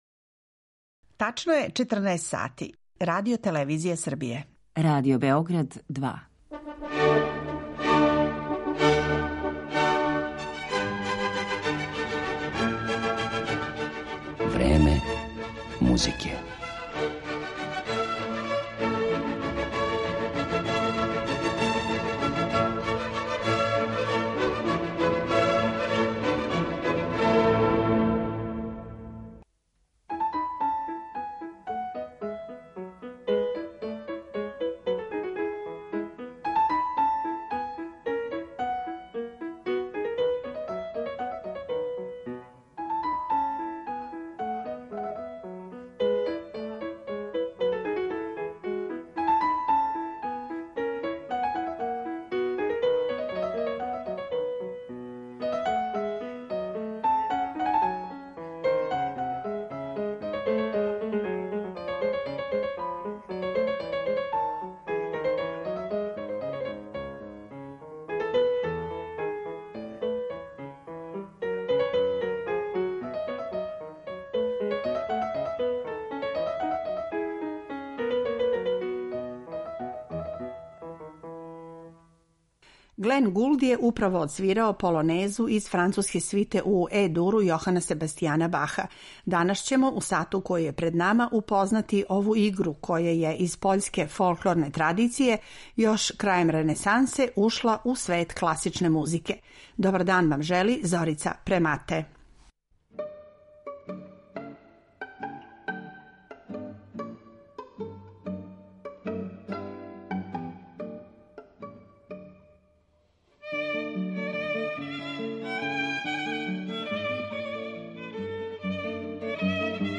Полонезе
Из пера Баха, преко Бетовена и Глинке, па до Дворжака, Чајковског и Шопена, полонезе су задржале свој свечани карактер, умерен темпо и пунктиран ритам на првој доби такта. Био је то плес пољске аристократије, који је, још у 18. веку, прихватила музичка Европа.